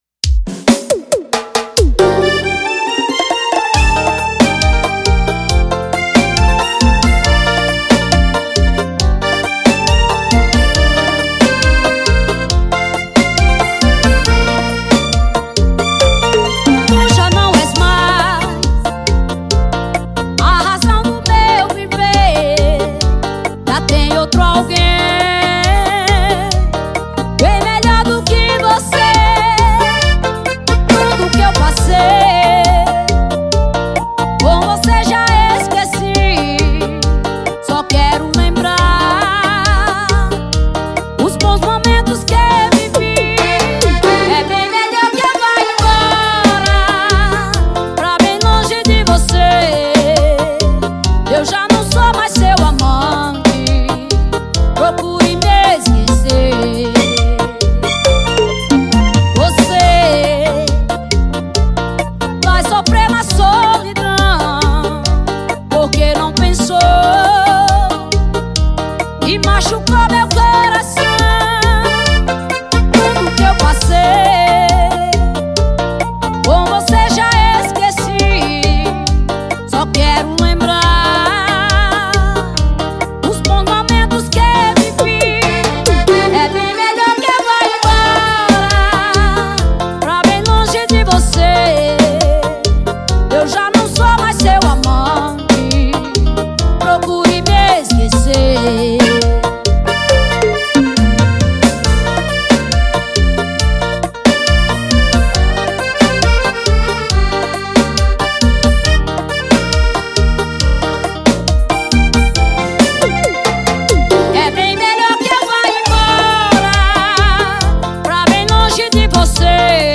Brega